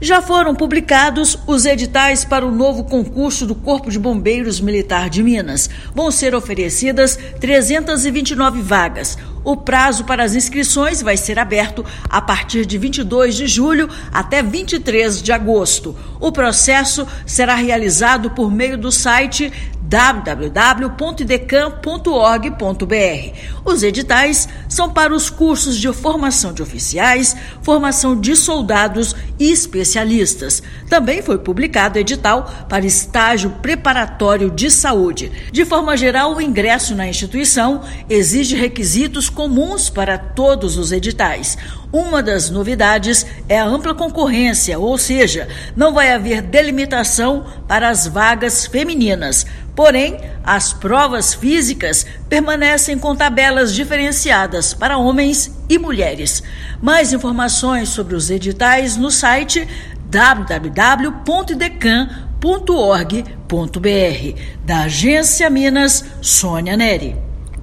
[RÁDIO] Corpo de Bombeiros Militar de Minas Gerais anuncia concurso com 329 vagas
Salário de militares pode chegar a R$ 11 mil; carreira em uma das corporações mais respeitadas do país também chama a atenção. Ouça matéria de rádio.